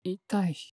戦闘 ダメージ ボイス 声素材 – Damage Voice